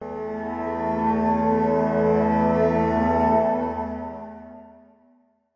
Glass Impact C
描述：An impact FX sample
标签： Electronic Impact FX
声道立体声